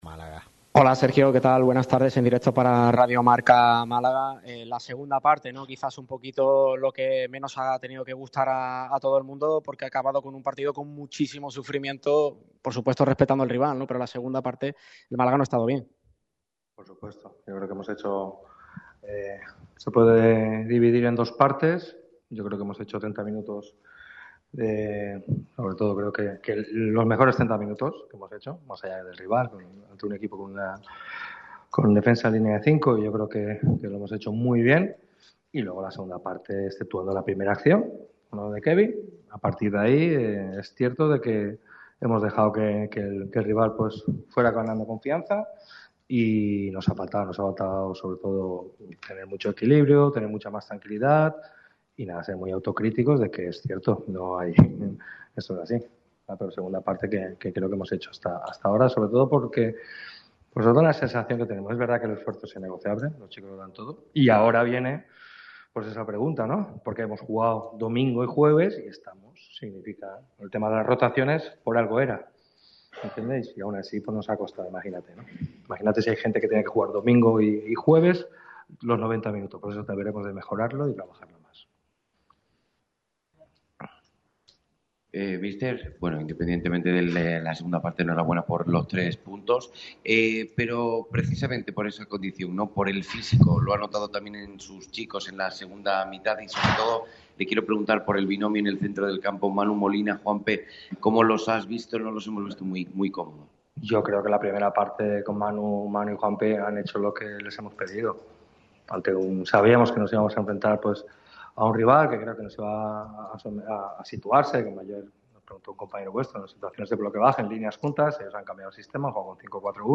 Al término del partido ante el Melilla habló Sergio Pellicer. El técnico malaguista atendió a los medios para valorar la victoria por la mínima de este jueves gracias al tanto de Dioni.